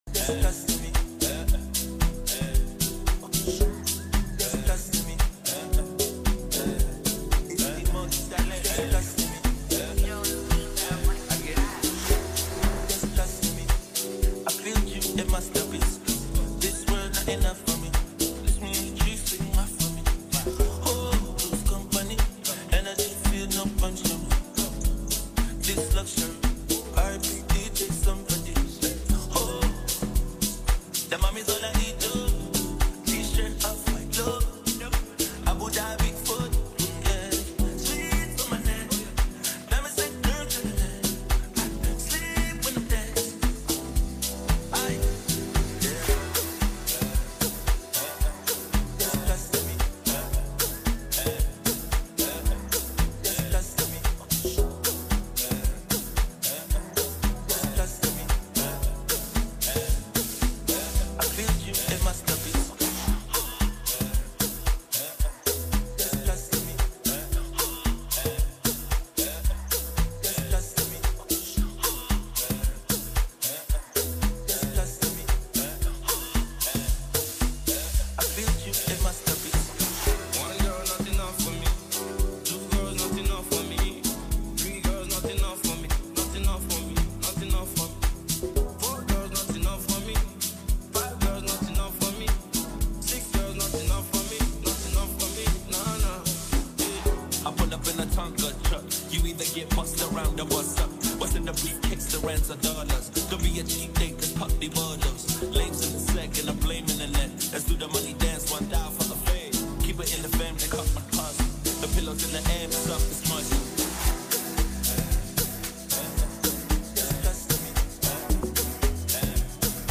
with a plethora of banging tracks and infectious mixes.